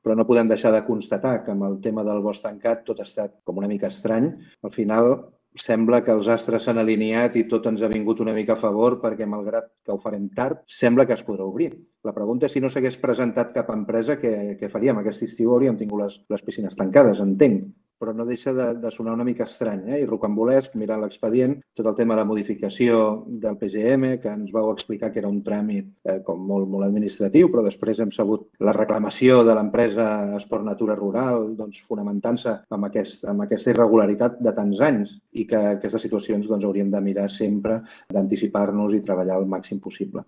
El portaveu del Grup Municipal de Junts per Cerdanyola,
Ple extraordinari Bosc Tancat